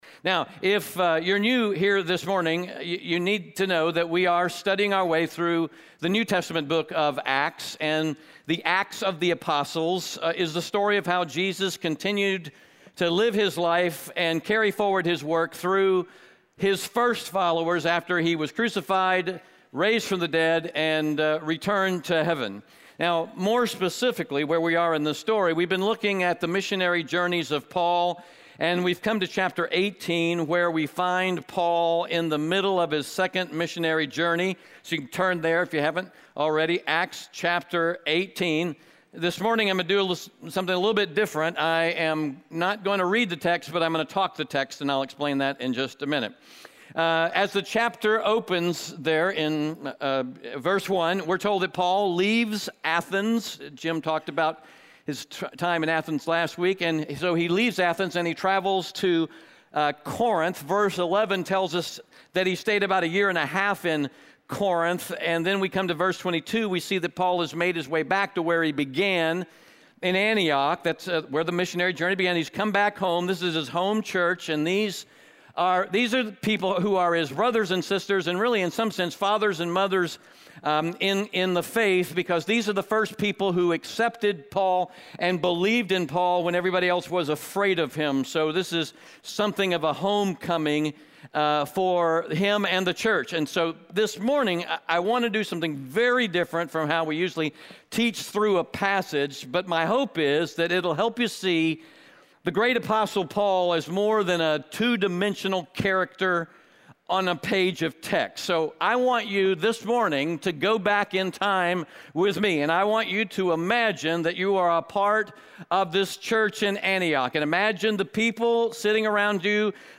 Audio Sermon Notes (PDF) Ask a Question *We are a church located in Greenville, South Carolina.